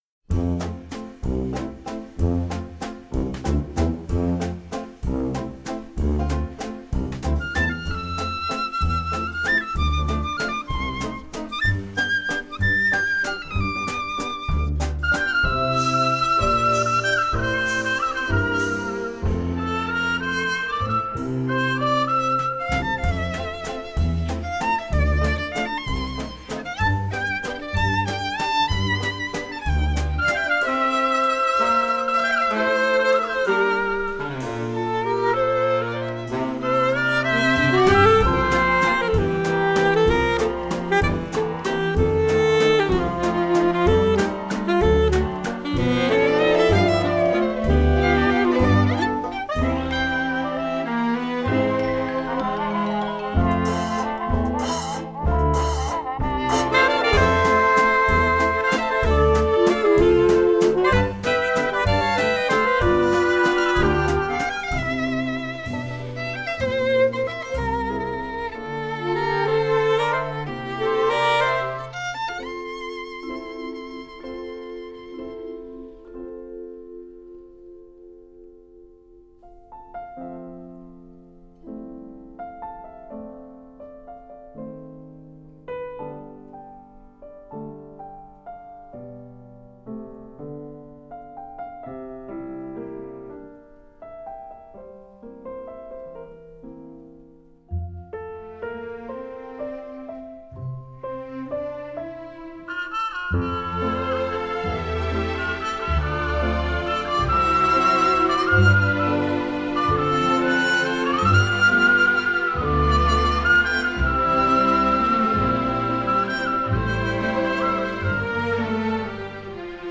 اهنگ بی کلام
Instrumental